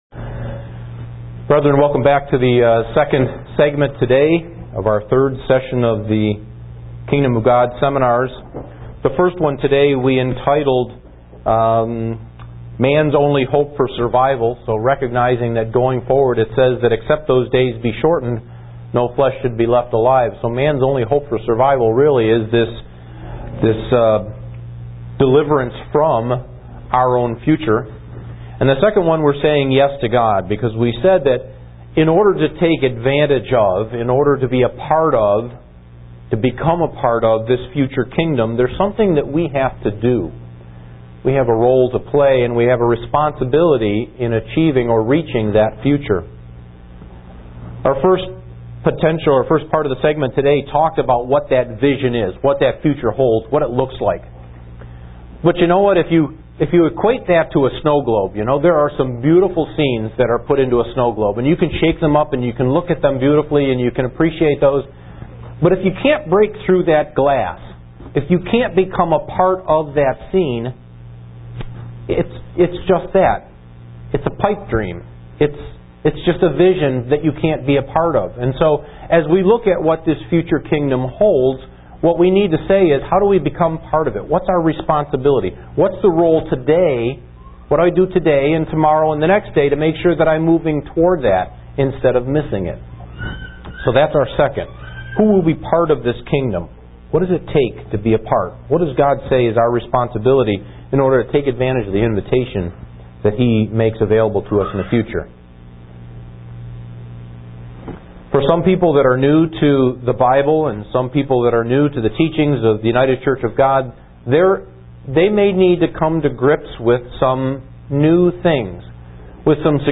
Given in Buffalo, NY
Kingdom of God Seminar 3: Part 2 UCG Sermon Studying the bible?